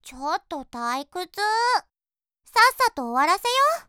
第五则配音片段：
乌噜可爱、活力、俏皮的属性，被声优演绎得惟妙惟肖~